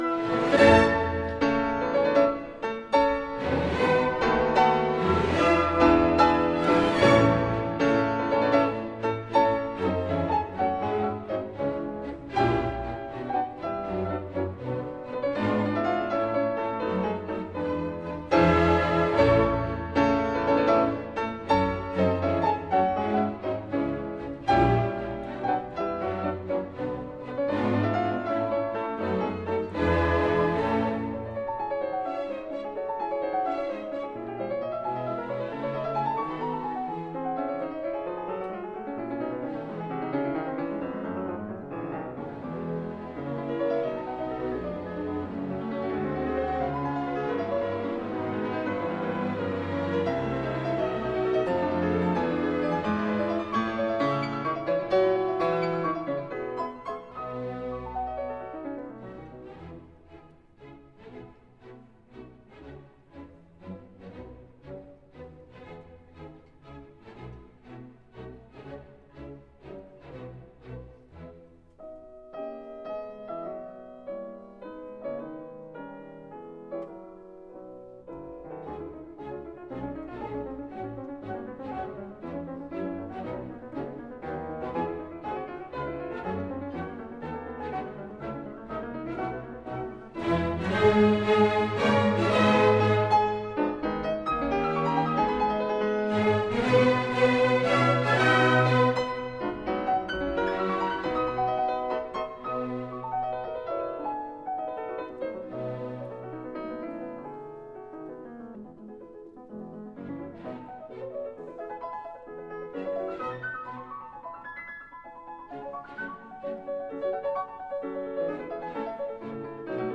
백중(百中)에 즈음하여 촌노는 그저 국태민안(國泰民安)을 기도할 뿐이다. 03-슈만 피아노협주곡a단조op.54-3.mp3